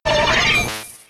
Cri de Mimitoss K.O. dans Pokémon X et Y.